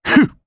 Shakmeh-Vox_Attack1_jp.wav